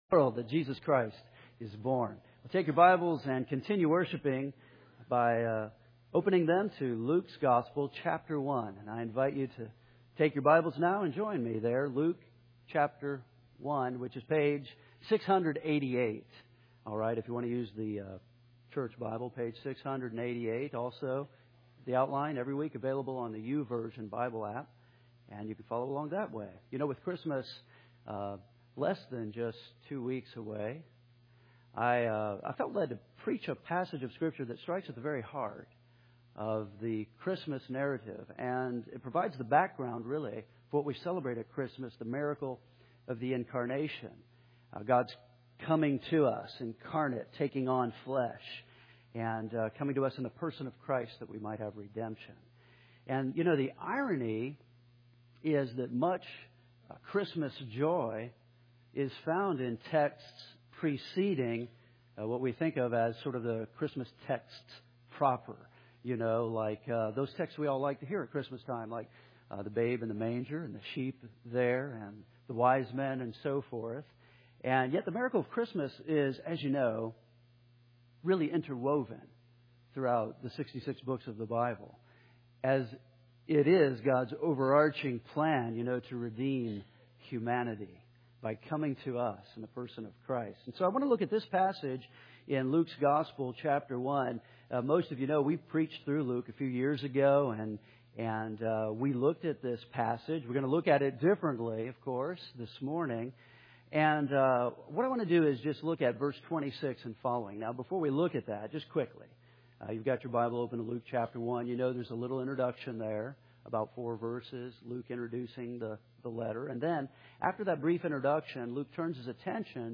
With Christmas just less than two weeks away, I felt led to preach a passage of Scripture that strikes at the very heart of the Christmas message and provides the background of the Christmas miracle of the incarnation, God’s coming to us, taking on flesh in the Person of Christ.